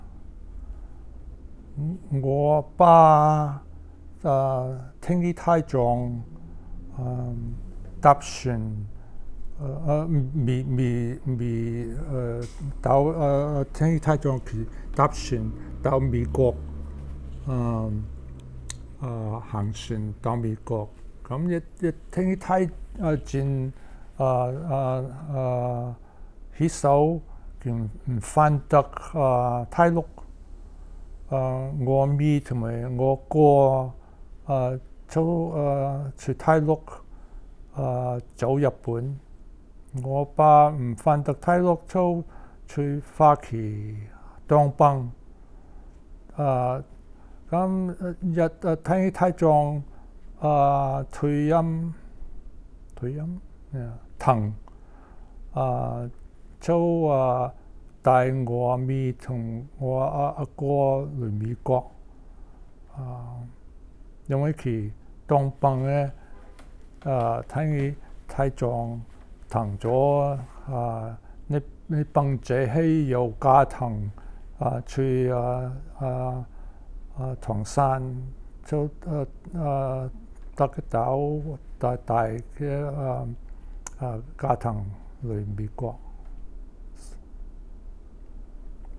Male, 73
digital wav file recorded at 44.1 kHz/16 bit on Zoom H2 solid state recorder
New York City
Dapeng dialect in Shenzhen, China